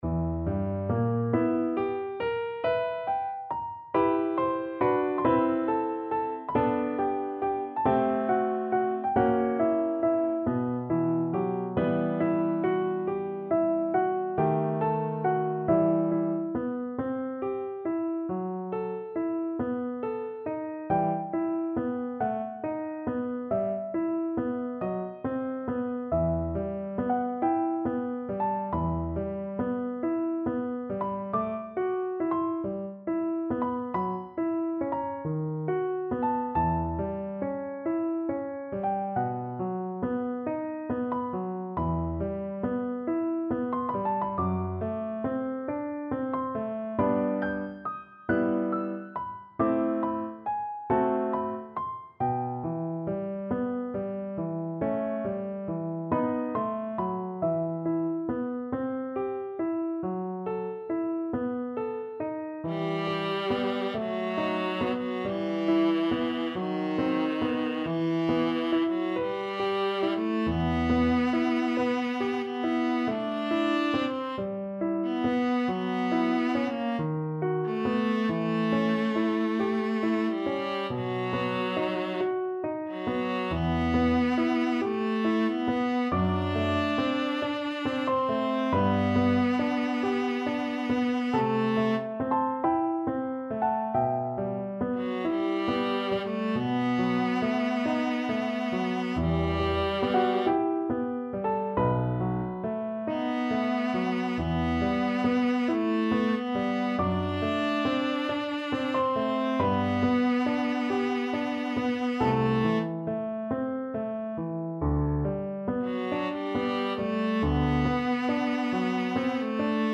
Viola
4/4 (View more 4/4 Music)
E minor (Sounding Pitch) (View more E minor Music for Viola )
Slow =c.46
Classical (View more Classical Viola Music)